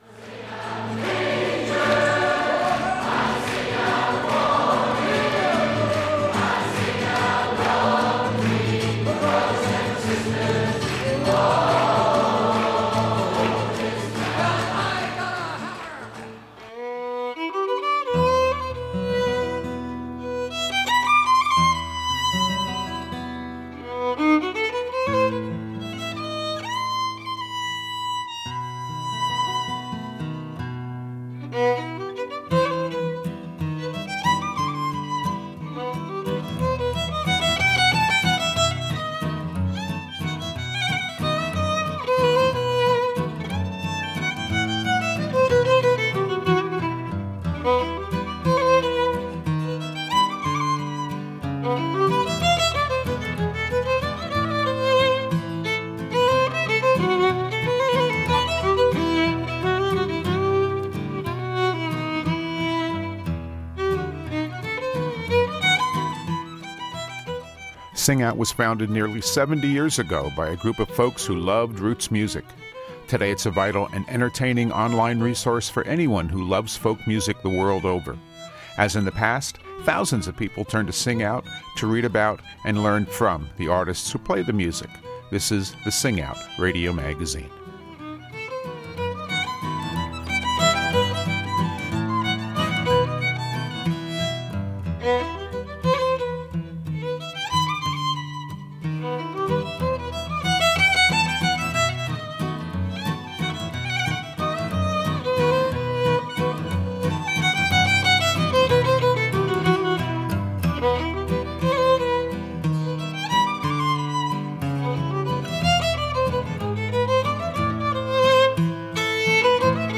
The concept of musicians playing together has been popular since the first time anyone sat down to exchange musical ideas. Duos have had a great deal of success, and for the next two shows, we’ll highlight a mix of great duos playing a variety of styles.